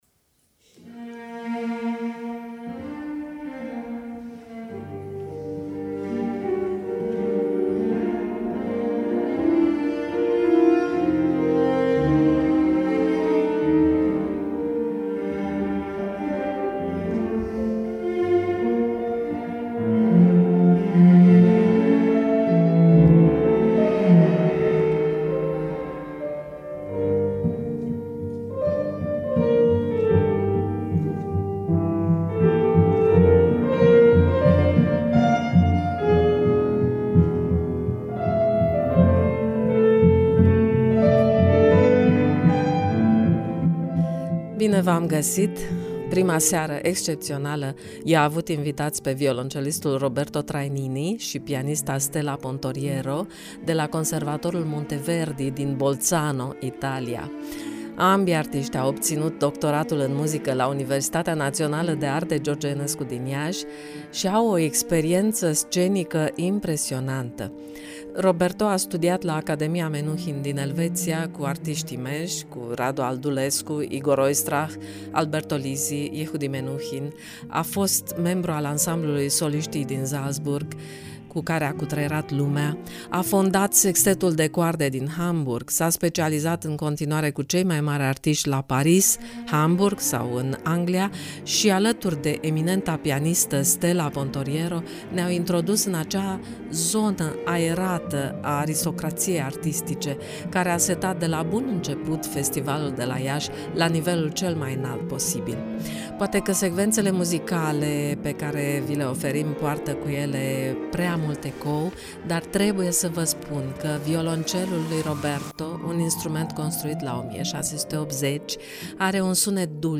Poate că secvențele muzicale din reportaj poartă cu ele mai mult ecou decât era necesar